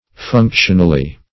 Functionally \Func"tion*al*ly\, adv.